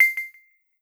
Success10.wav